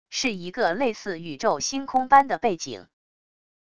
是一个类似宇宙星空般的背景wav音频